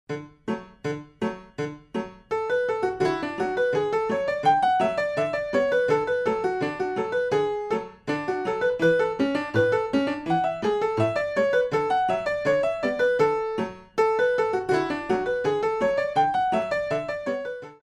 Classical Arrangements for Pre Ballet Classes